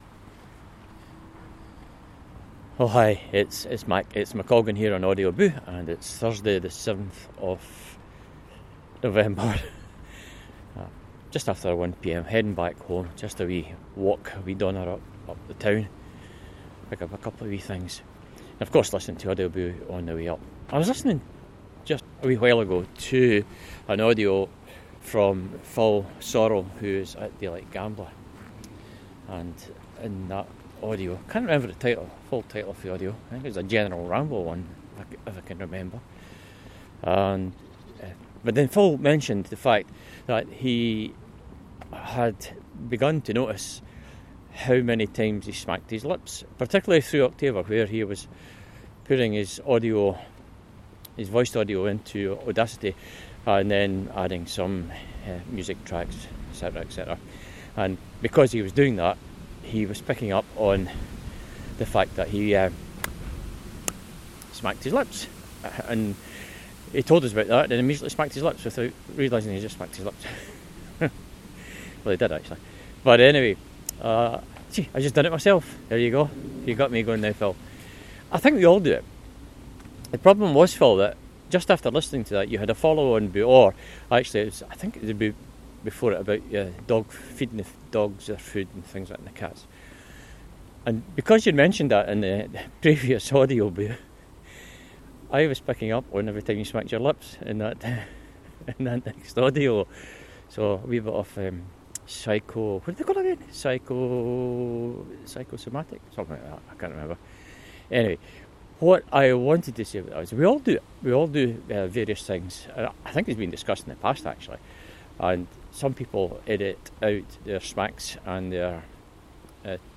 Just a bit of fun with our personalised vocal fillers.